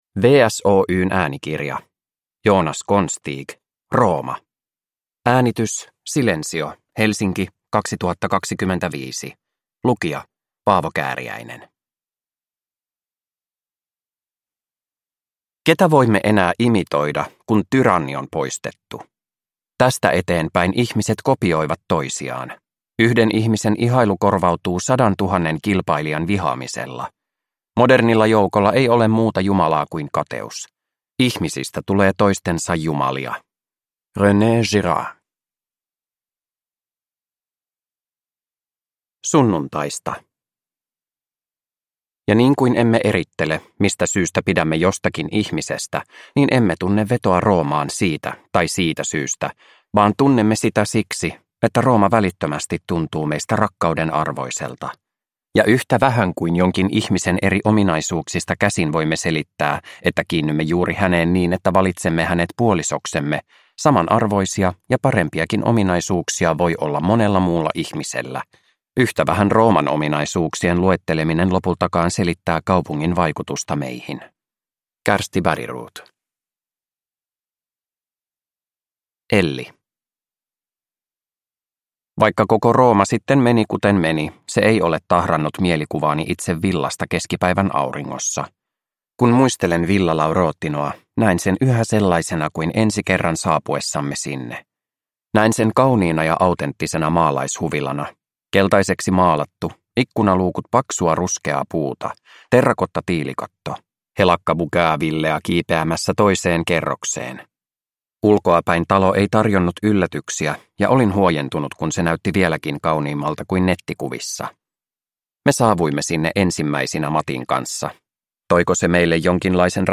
Rooma (ljudbok) av Joonas Konstig